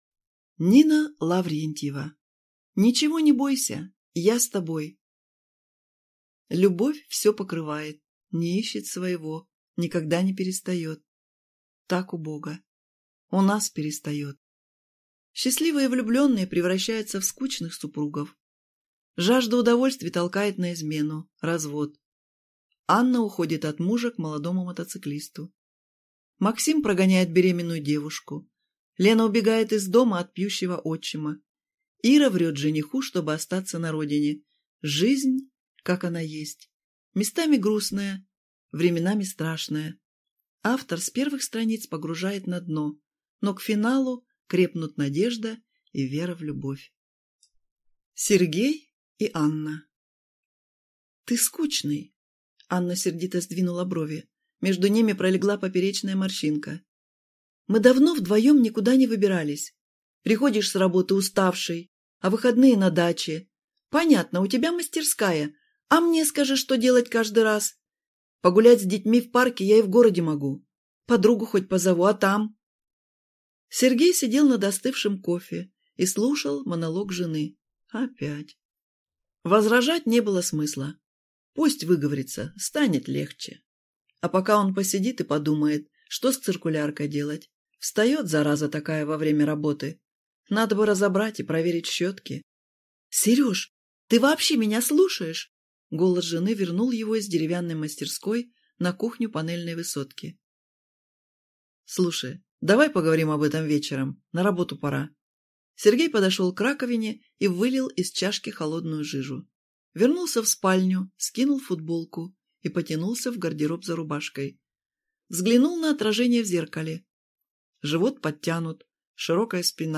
Аудиокнига Ничего не бойся, я с тобой | Библиотека аудиокниг